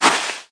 cat01.mp3